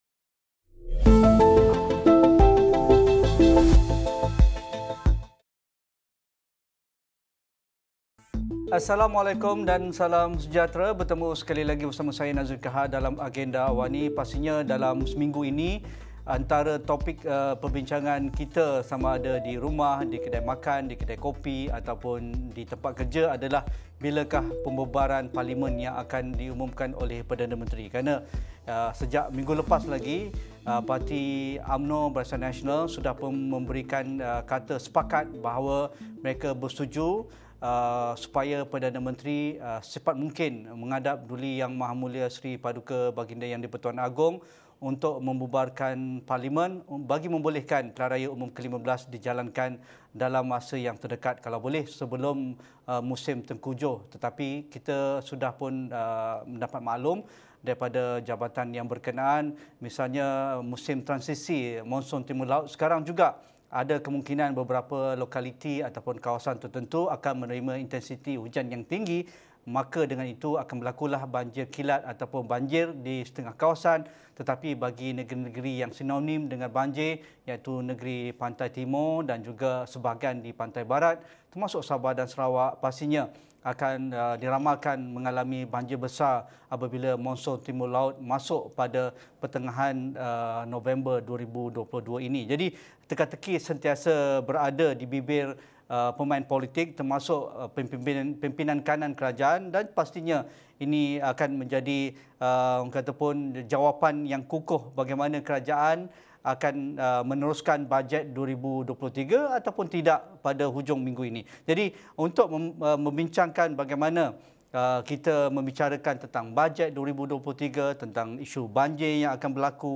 Persoalan mengenai tarikh PRU15 semakin hangat dibincangkan apatah lagi spekulasi kemungkinan pembubaran Parlimen akan berlaku tidak lama lagi. Apa pertimbangan yang perlu diambil kira sebelum mandat pilihan raya dikembalikan kepada rakyat? Diskusi 9 malam